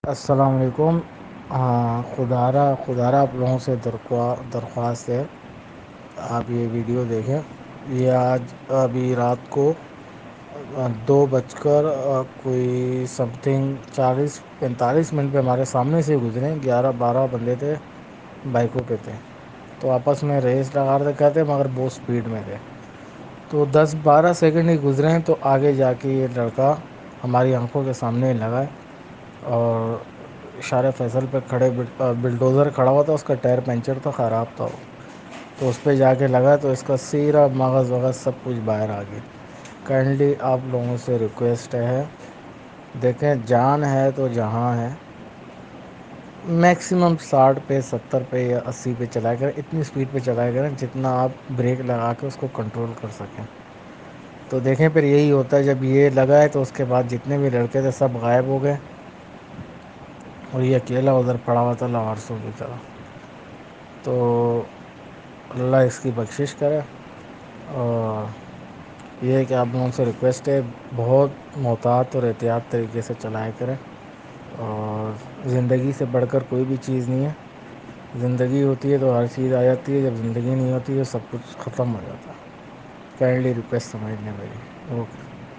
مگر اگر آنکھوں دیکھے اس آدمی کی آواز آپ کو بتا سکتی ہے کہ واقعہ کتنا خوفناک ہے۔